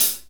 HAT R B CH0F.wav